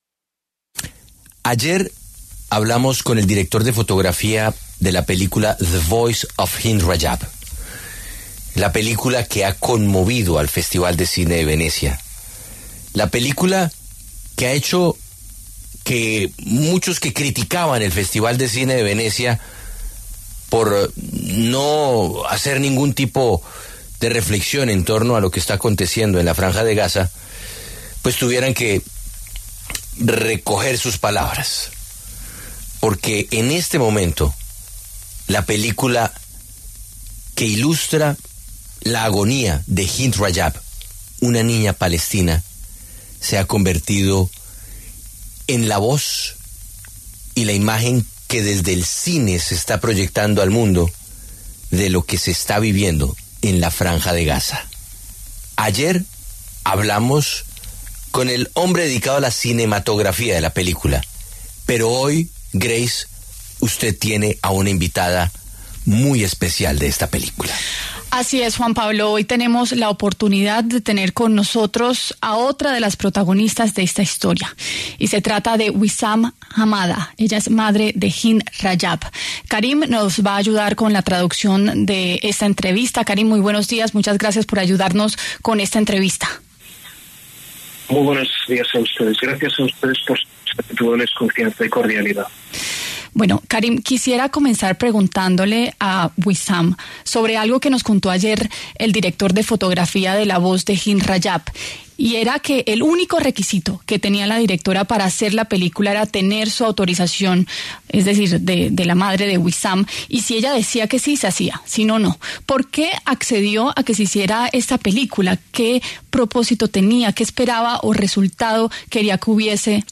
El dramático testimonio